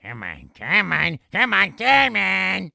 Voice clip of Wario taunting in Mario Power Tennis
MPT_Provoke_Wario.mp3